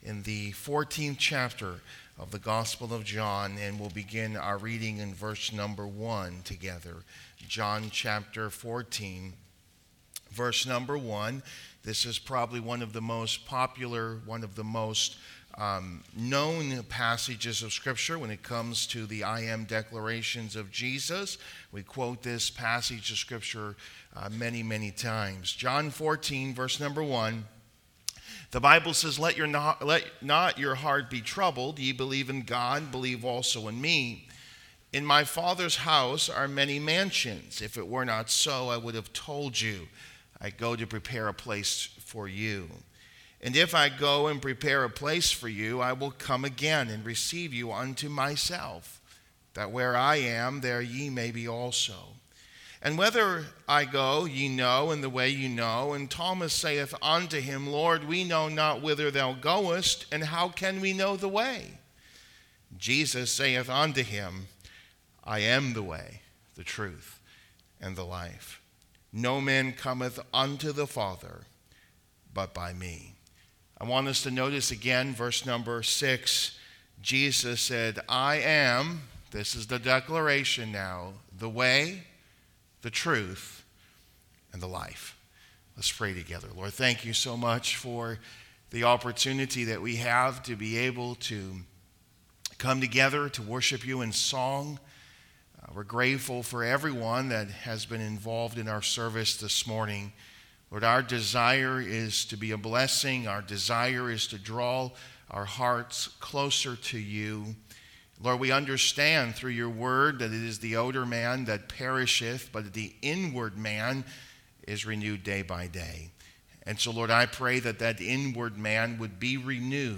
The Way, the Truth, the Life | Sermons